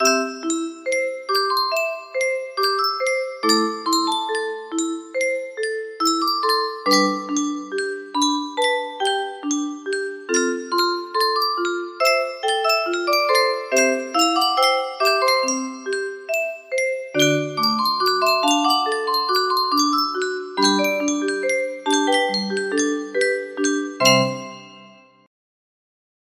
The whistling part!